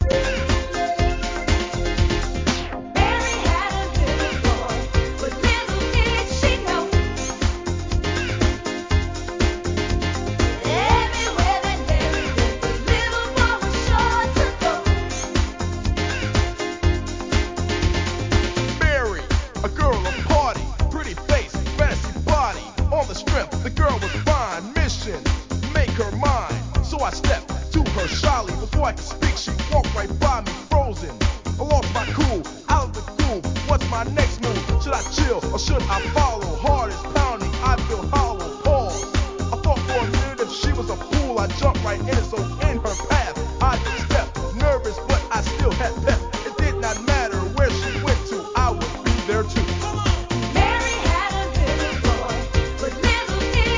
HIP HOUSE